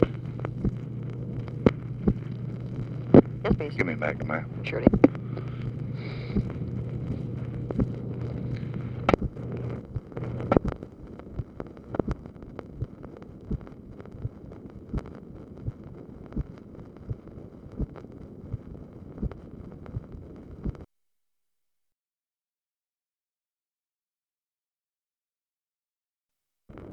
LBJ ASKS OPERATOR TO PLACE CALL TO ROBERT MCNAMARA; CONVERSATION NOT RECORDED
Conversation with TELEPHONE OPERATOR
Secret White House Tapes